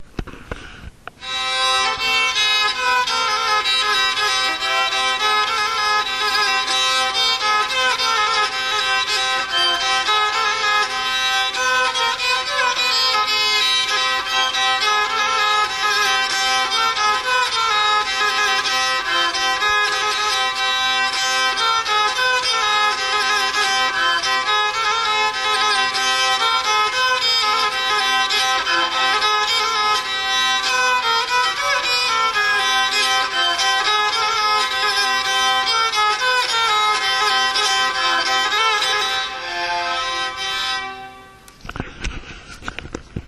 fidel płocka
fidel-plocka.mp3